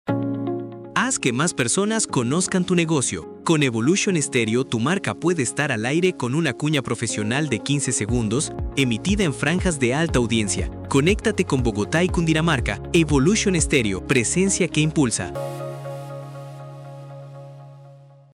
Audio institucional de Evolution Stereo   Presentación de la emisora y respaldo del servicio.